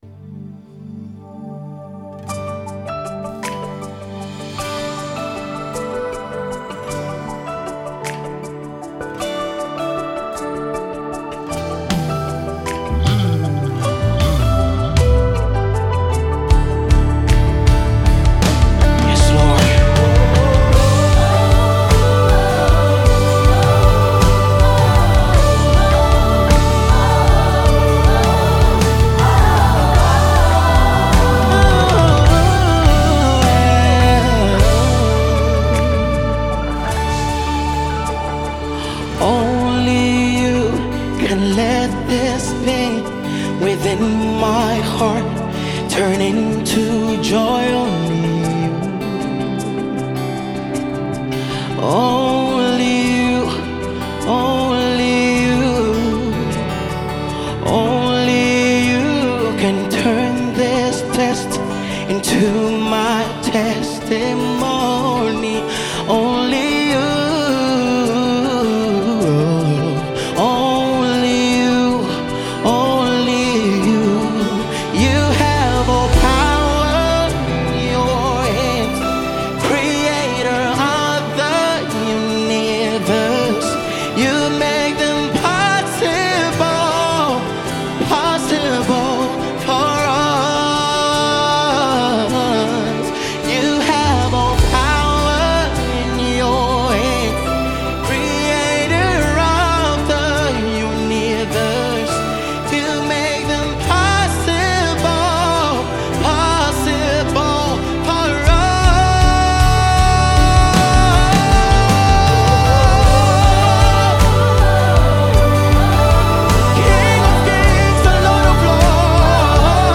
power song
Gospel